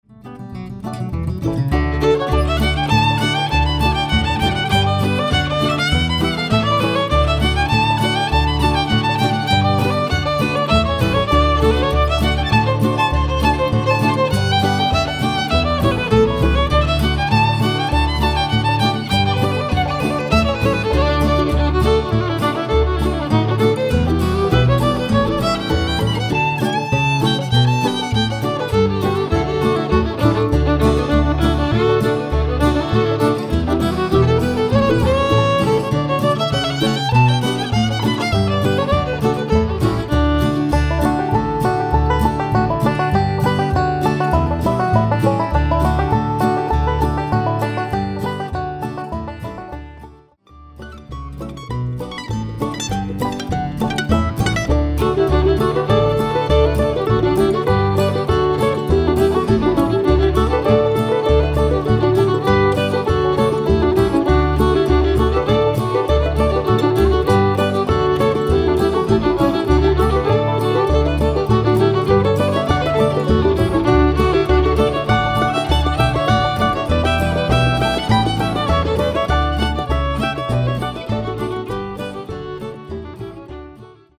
An instrumental album
mostly from the Bluegrass and Old-Time repertoire